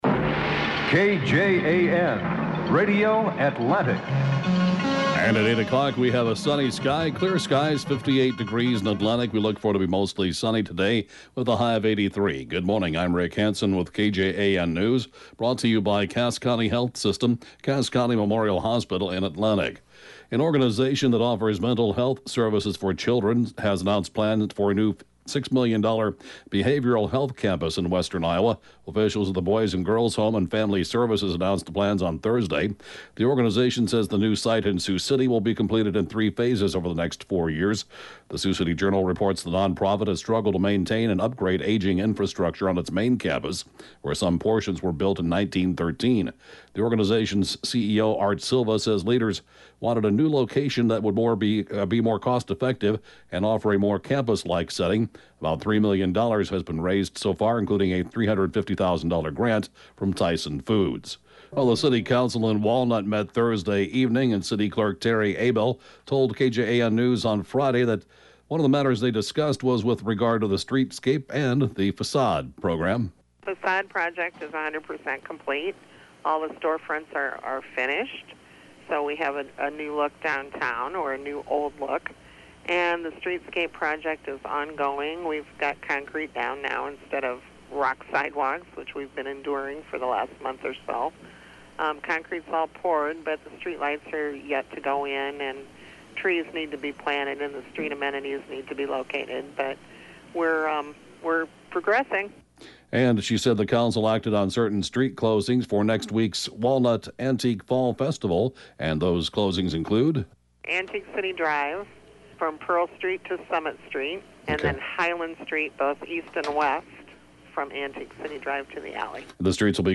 (Podcast) KJAN Morning News & funeral report, 9/9/2017